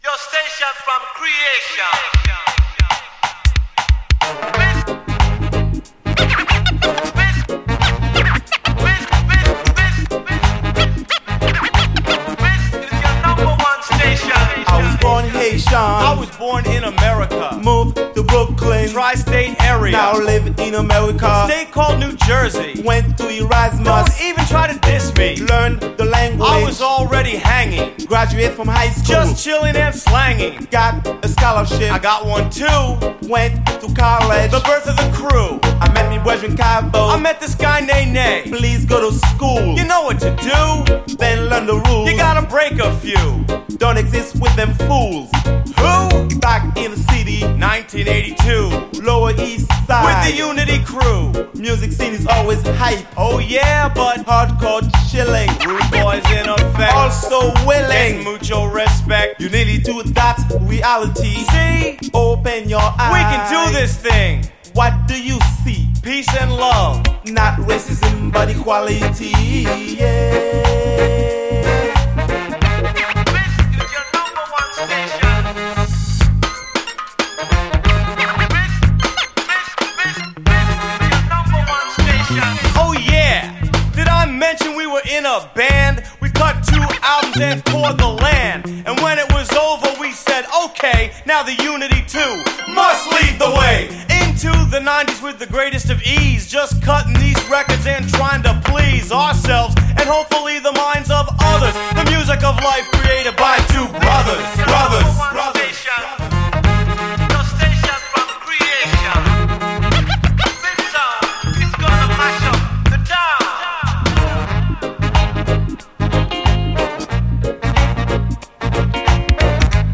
REGGAE, HIPHOP
ニュースクール的な自由な空気をまとったNY産ラガマフィン・ヒップホップ